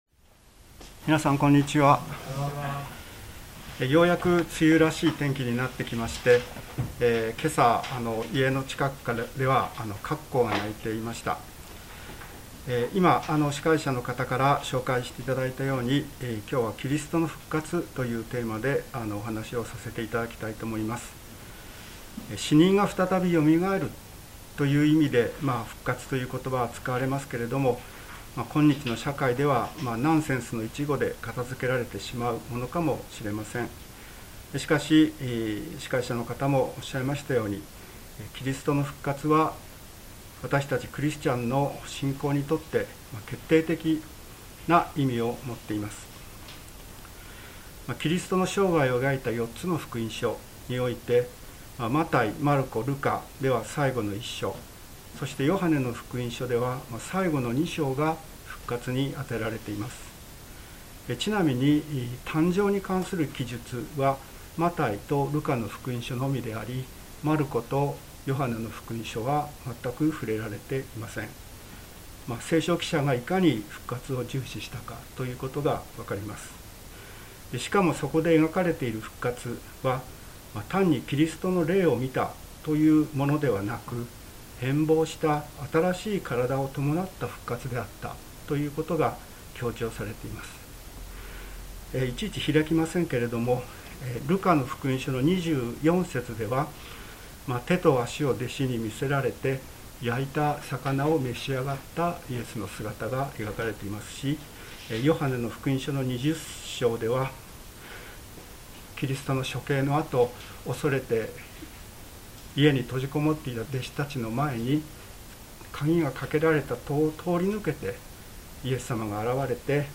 聖書メッセージ